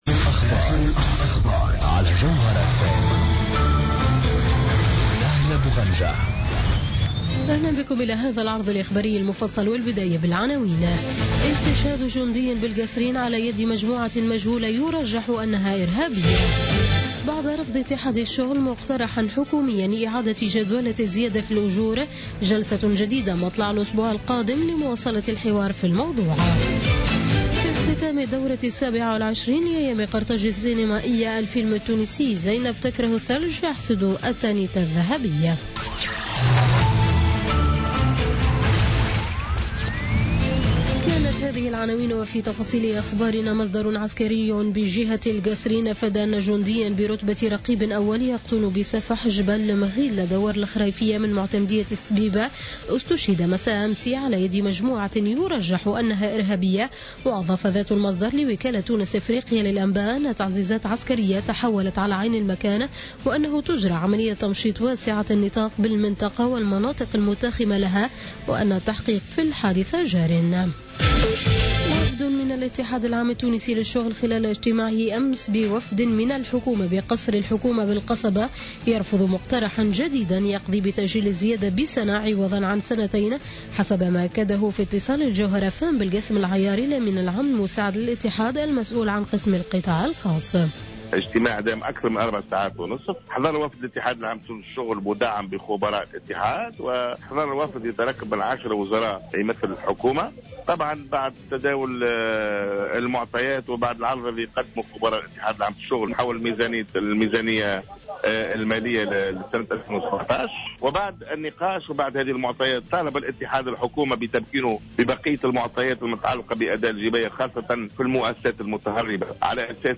نشرة أخبار منتصف الليل ليوم الأحد 6 نوفمبر 2016